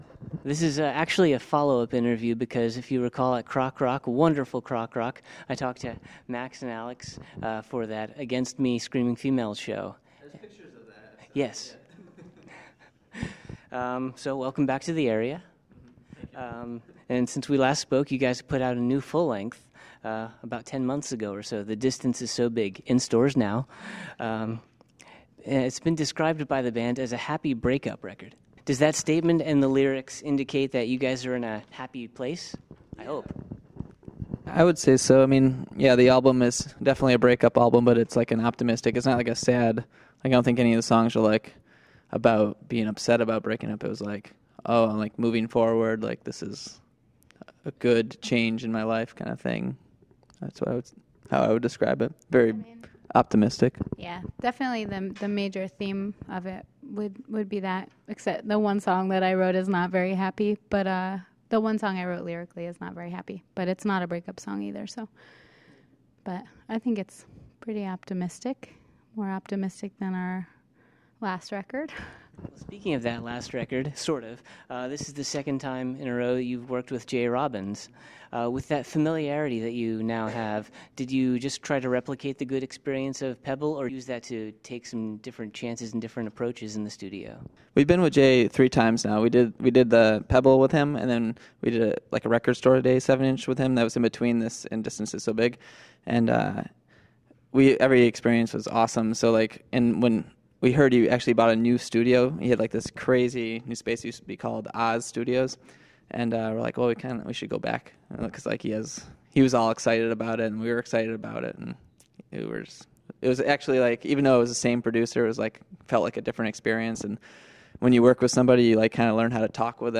Exclusive: Lemuria Interview 2.0
42-interview-lemuria.mp3